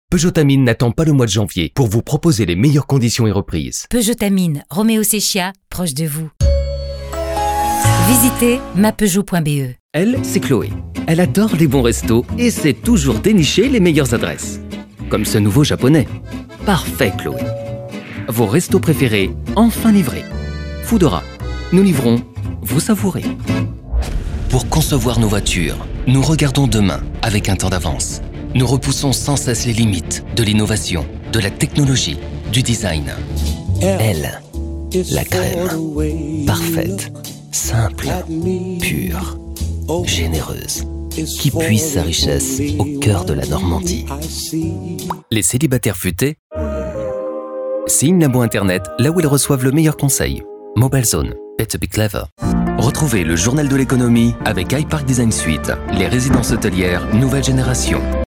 Démos Spots TV
Les démos sur cette page sont des extraits de projets réels livrés et mixés par les studios respectifs.